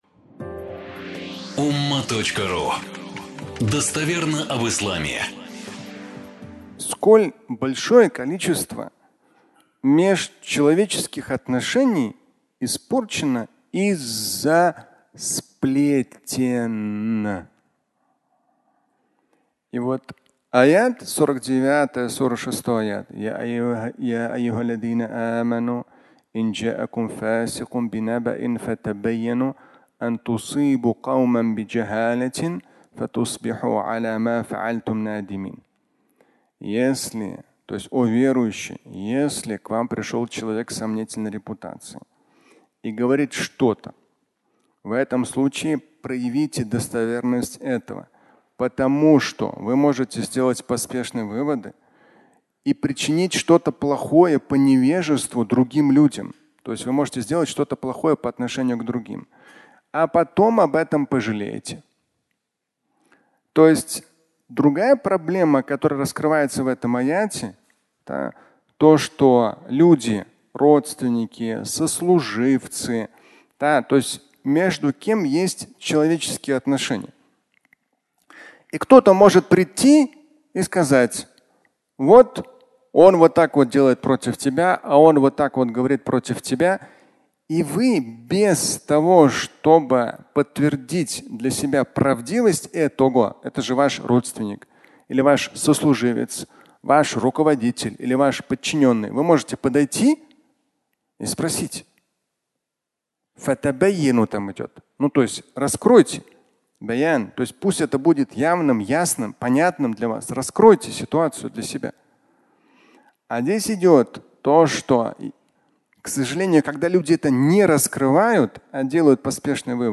Твой близкий (аудиолекция)